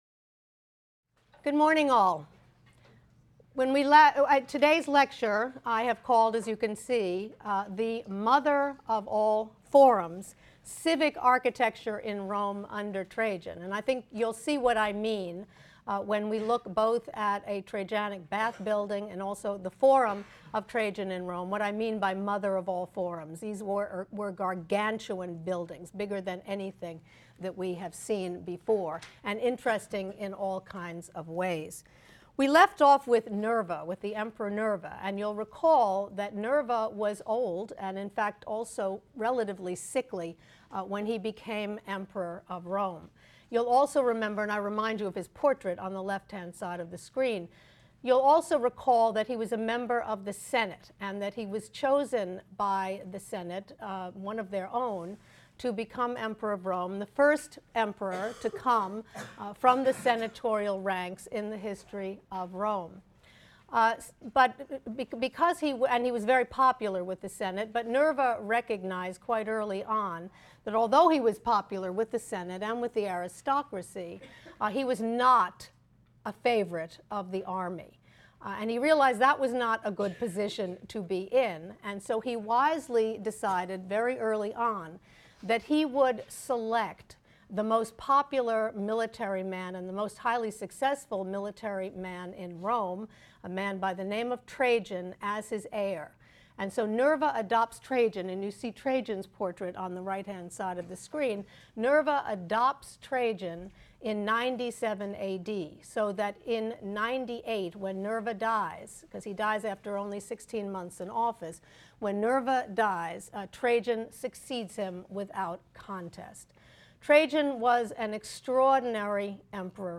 HSAR 252 - Lecture 14 - The Mother of All Forums: Civic Architecture in Rome under Trajan | Open Yale Courses